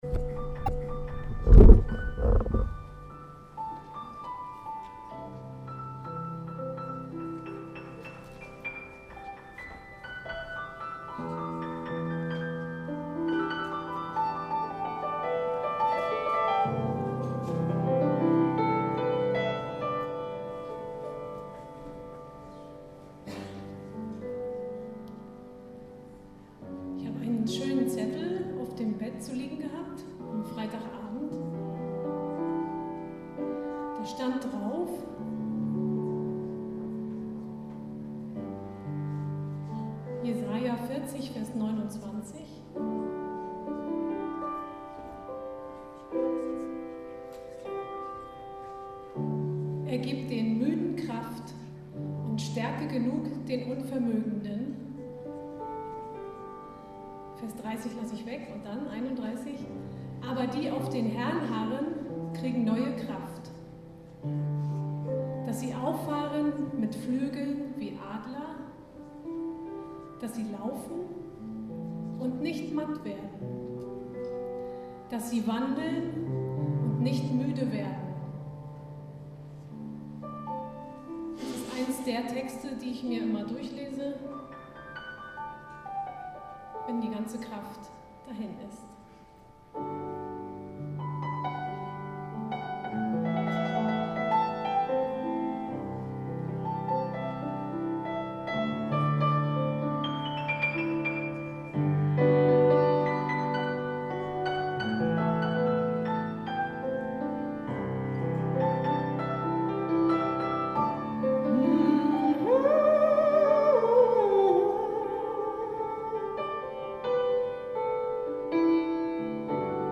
tagung2010_vortrag2.mp3